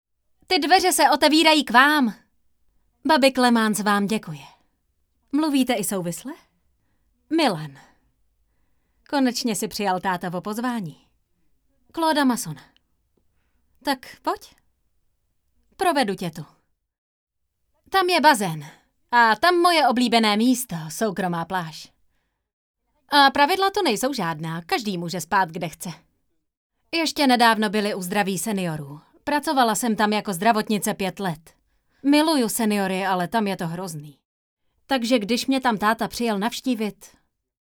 ukázka 1 – Dabing